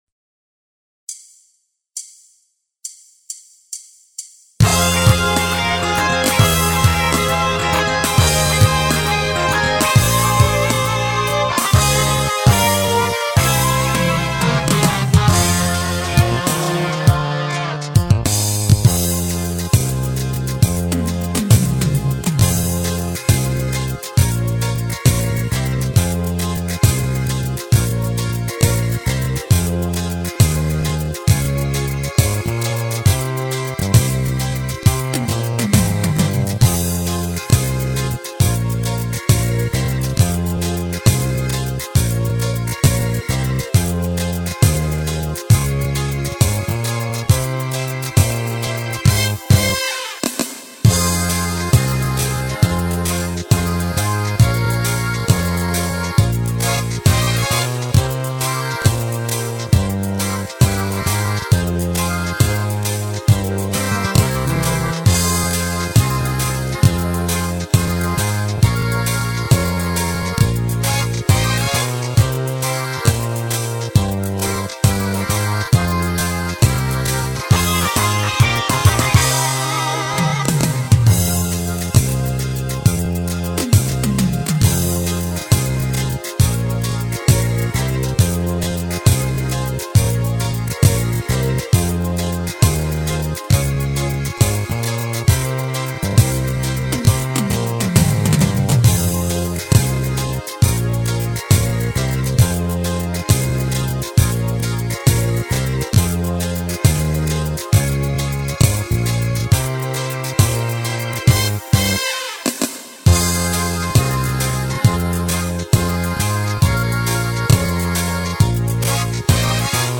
Поэтому я приподнял оную на полтона.